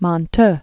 (mŏn-t, môɴ-tœ), Pierre 1875-1964.